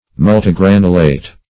Search Result for " multigranulate" : The Collaborative International Dictionary of English v.0.48: Multigranulate \Mul`ti*gran"u*late\, a. [Multi- + granulate.] Having, or consisting of, many grains.